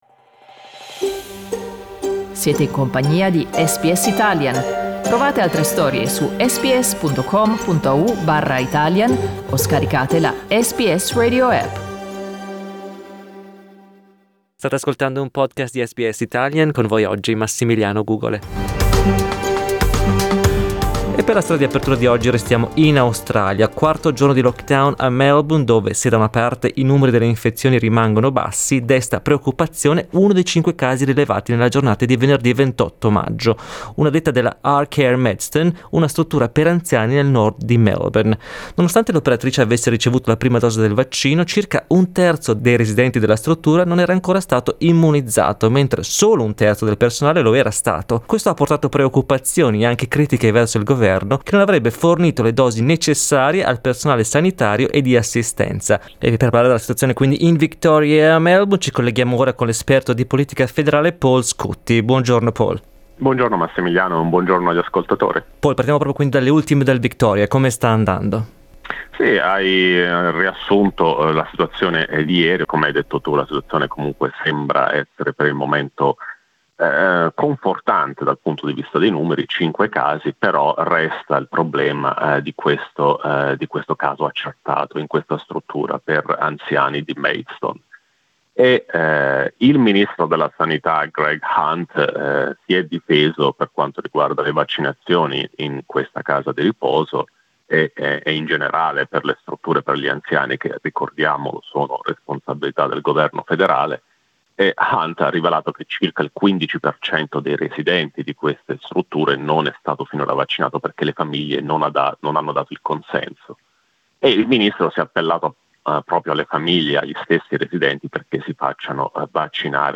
Riascolta qui l'intervento dell'analista politico